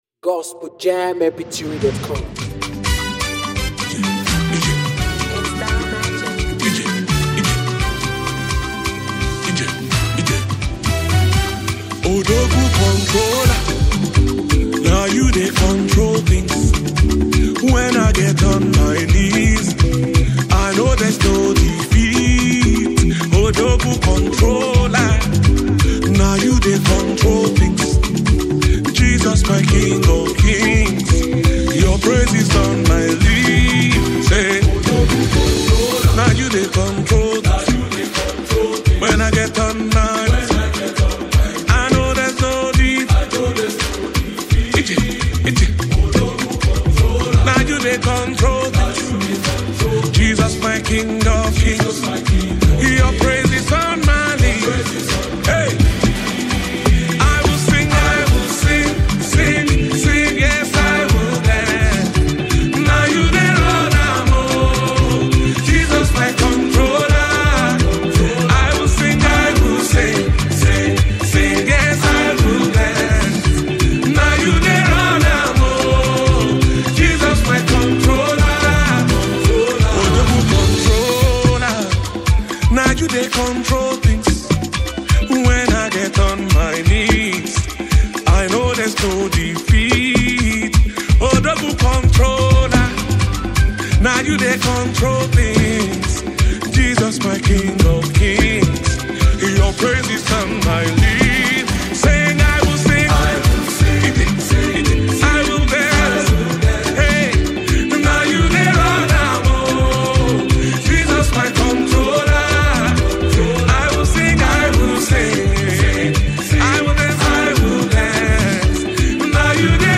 a powerful and uplifting gospel anthem
A must-listen for lovers of spirit-filled praise music.